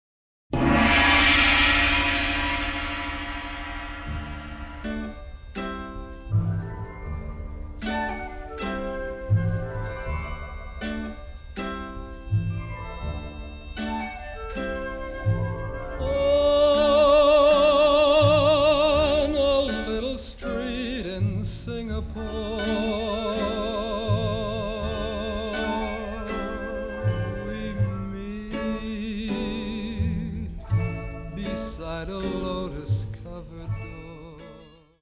Two remastered Hi-Fi LPs
along with a small combo.